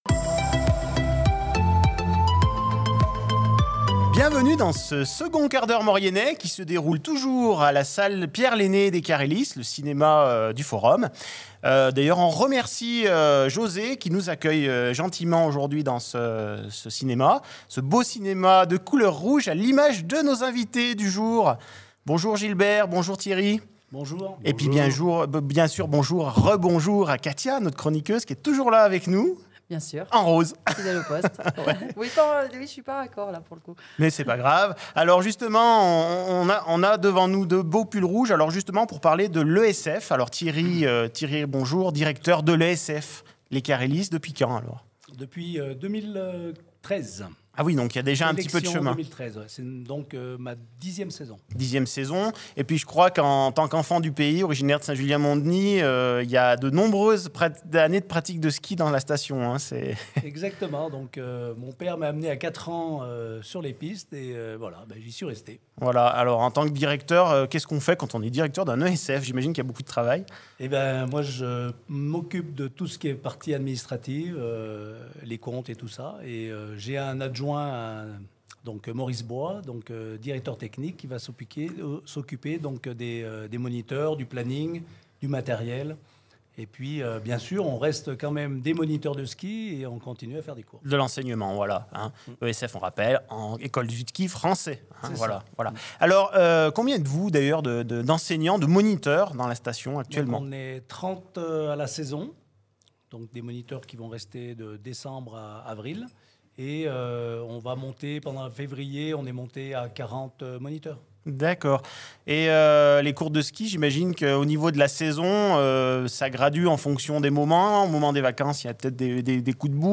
Dans cet épisode, nous avons l'honneur d'accueillir deux invités d'exception.